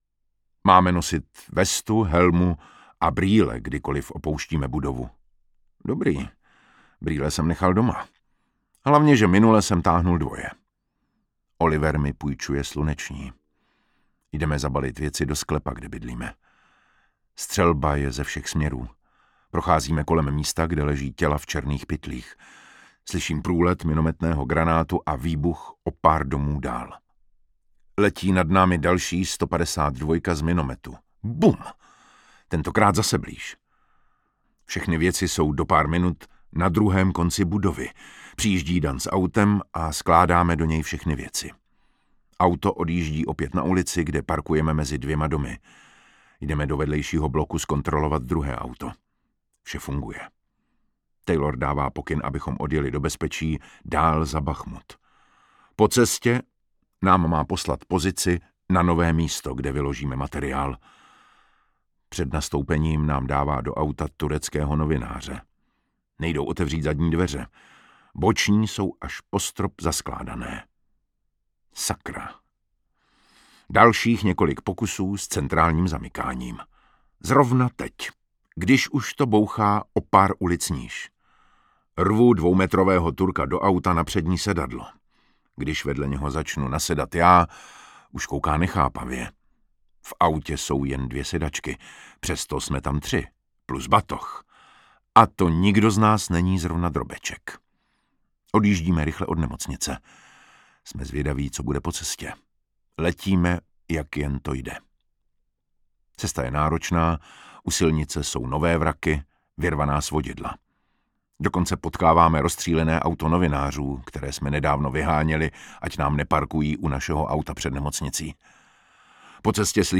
Audiobook
Read: David Matásek